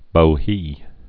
(bō-hē)